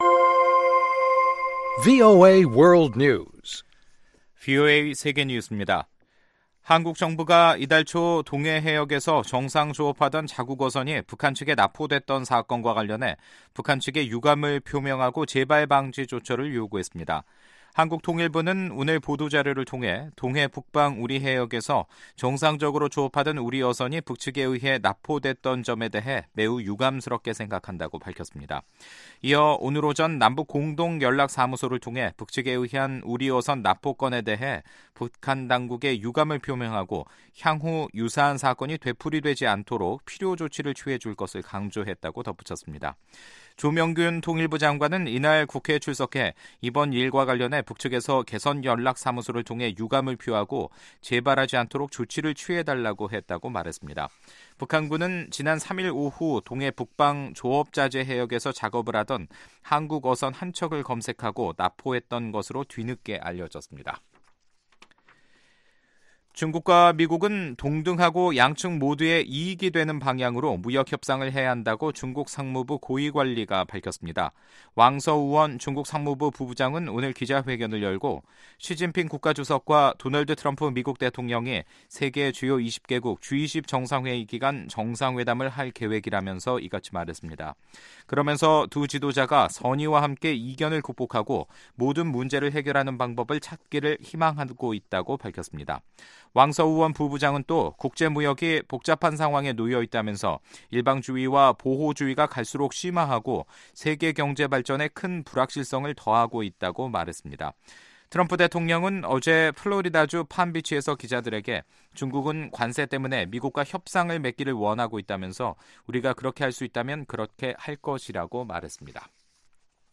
VOA 한국어 간판 뉴스 프로그램 '뉴스 투데이', 2018년 11월 23일 2부 방송입니다. 국제 원자력기구 사무총장은 8월 보고서 발표 이후에도 북한 영변에서 움직임이 관측돼 왔다며 사찰이 이뤄져야 이 활동들의 본질과 목적을 확인할 수 있다고 밝혔습니다. 미국 언론들은 싱가포르 공동선어에 대한 미-북 간의 근본적인 해석 차이가 협상을 답보 상태로 만들었다고 진단했습니다.